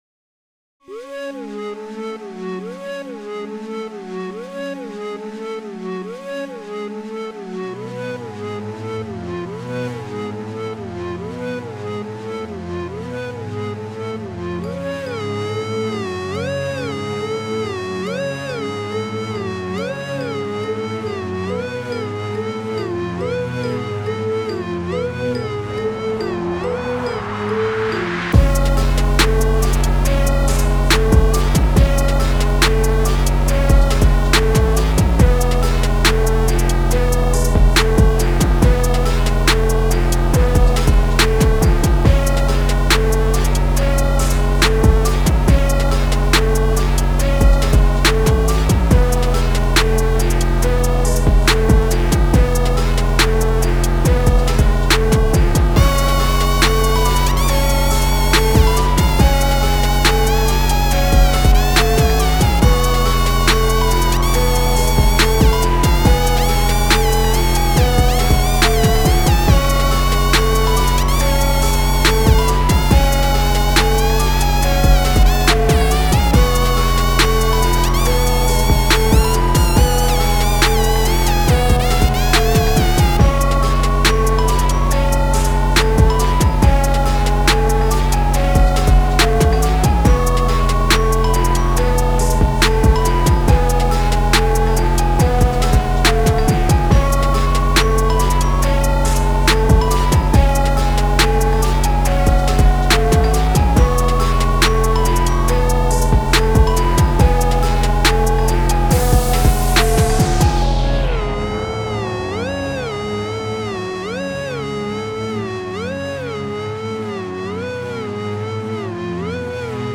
荒々しい雰囲気を出すために割れ気味のリードシンセを用いたTrap風のビートに仕上げている。